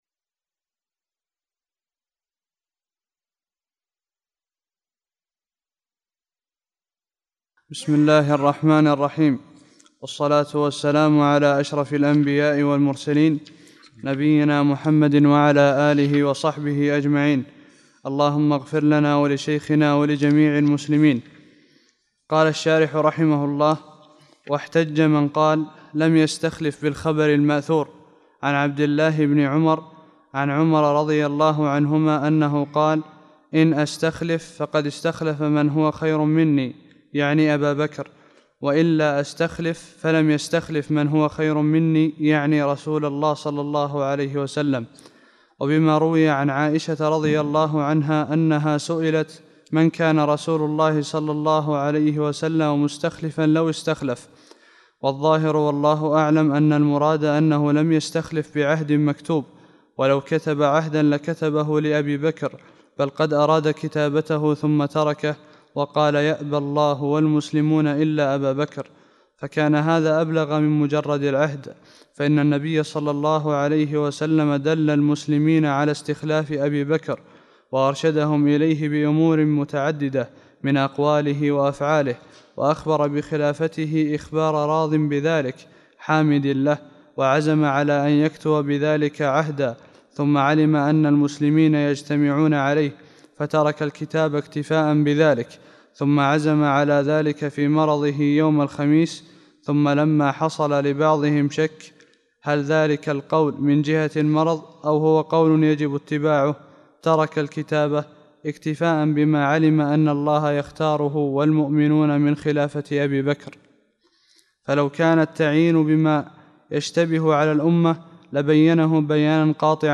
48- الدرس الثامن والأربعون